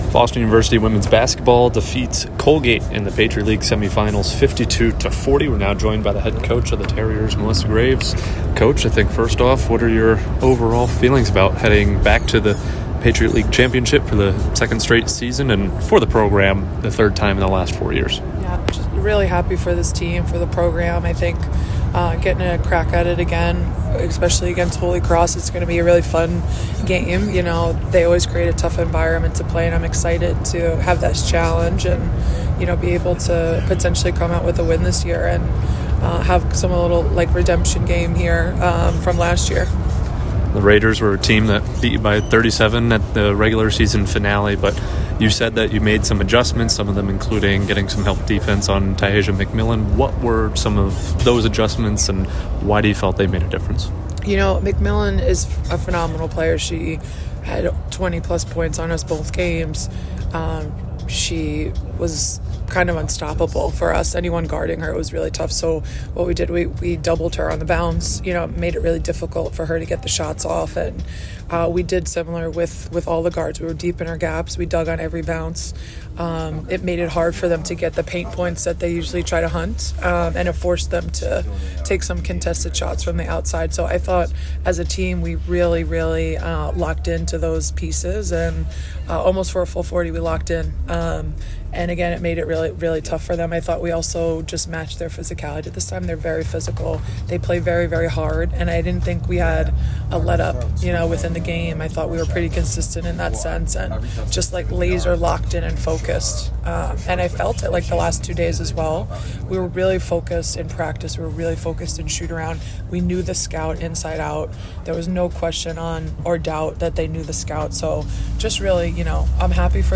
WBB_PL_Semifinal_Postgame.mp3